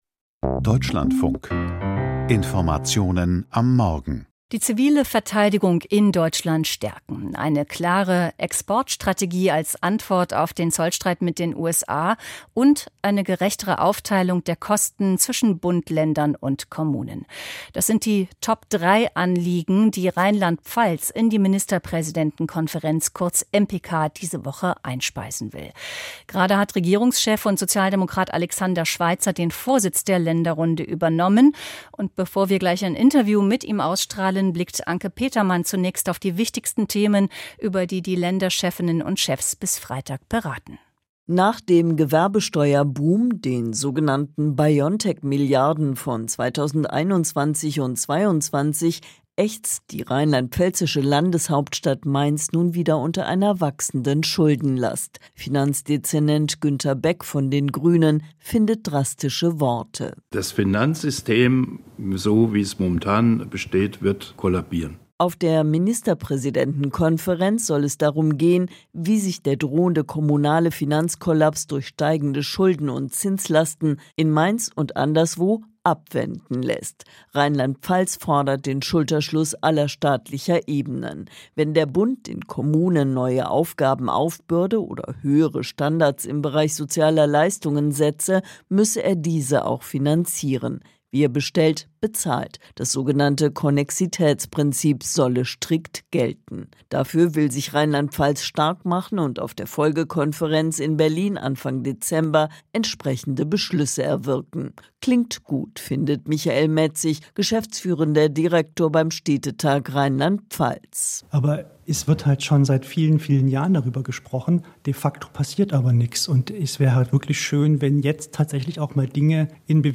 Ministerpräsidentenkonferenz in Mainz - Interview mit Alexander Schweitzer